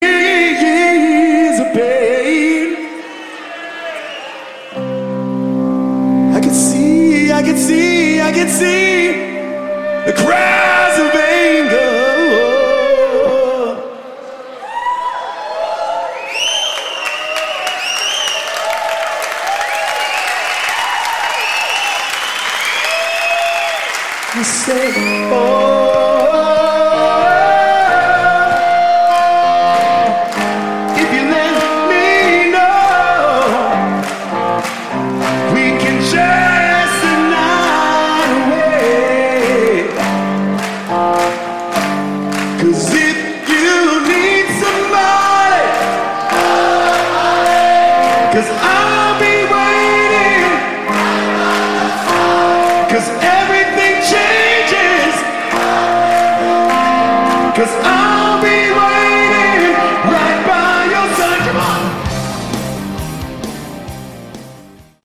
Category: Melodic Rock
Vocals, Guitars, Keyboards
Rhythm Guitars, Acoustic Guitars, Keyboards, Backing Vocals
Drums, Backing Vocals
Bass, Backing Vocals
Lead Guitars, Backing Vocals